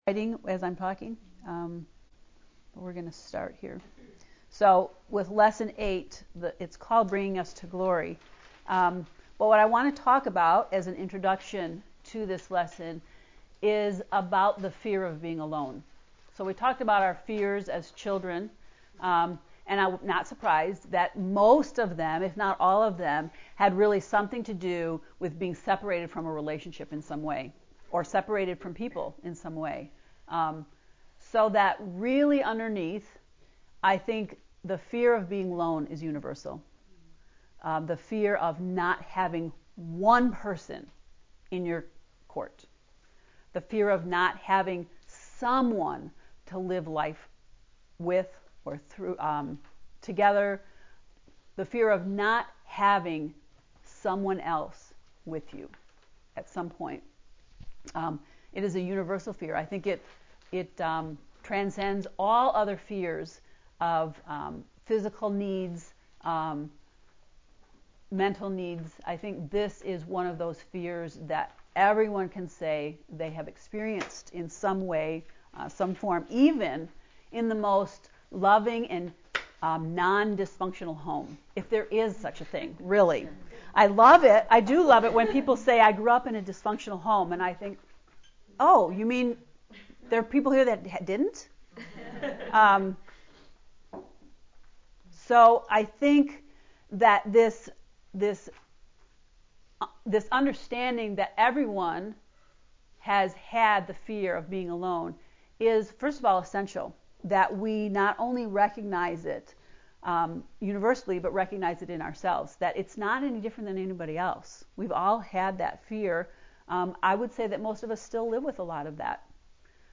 To listen to the Hebrews 8 lecture, “Bringing Us To Glory” click below:
heb-lecture-8.mp3